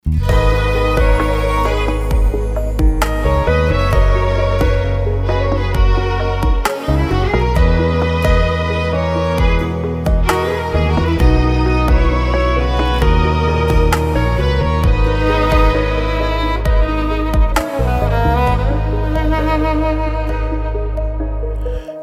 رینگتون غم انگیز، رمانتیک و بی کلام